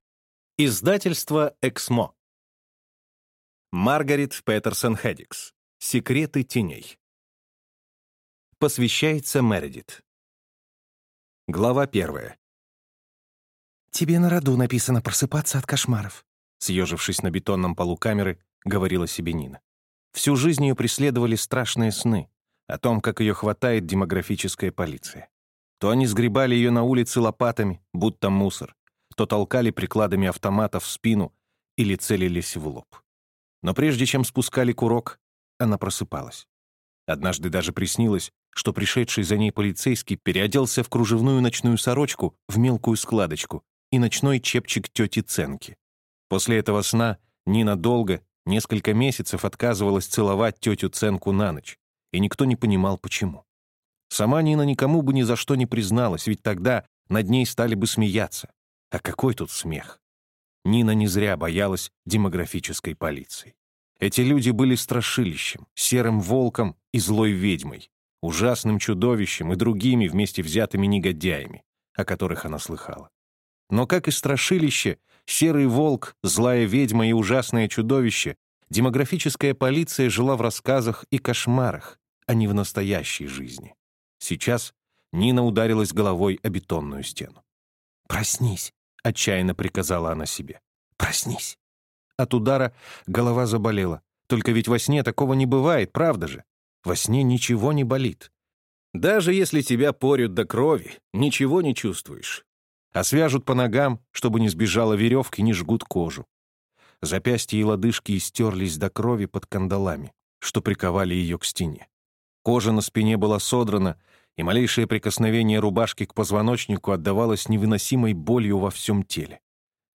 Аудиокнига Секреты теней | Библиотека аудиокниг